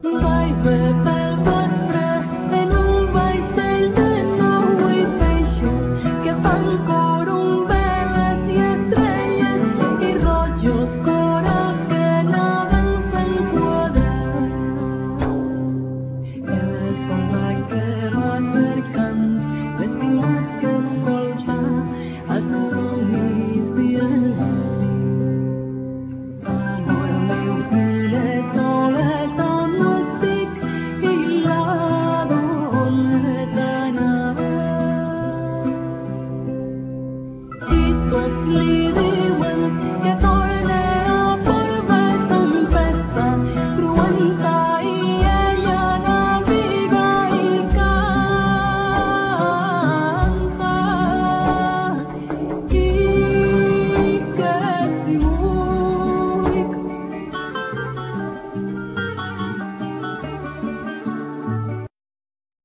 Viella,Rebec,Cello